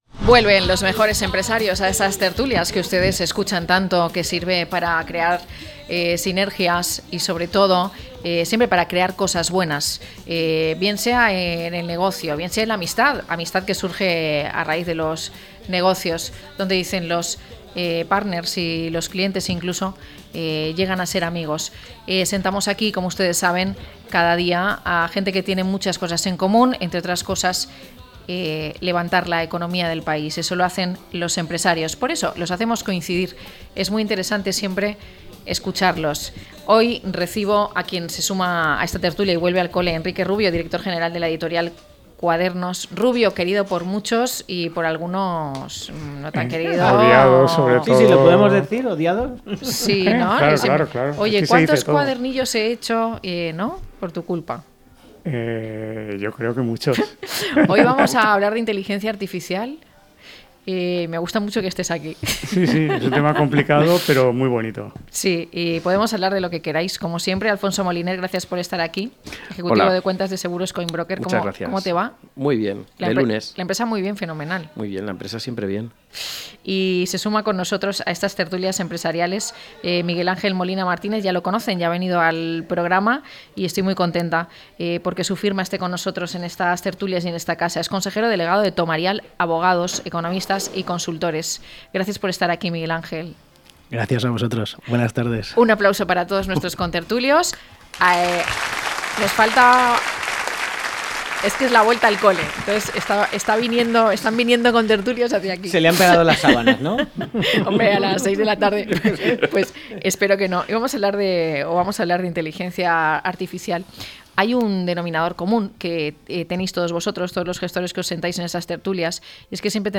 0916-LTCM-TERTULIA.mp3